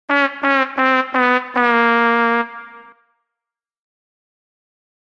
На этой странице собрана коллекция звуковых эффектов, символизирующих неудачу, провал и комичные поражения.
Мультипликационный звук облома ква ква ква ква когда происходит что-то плохое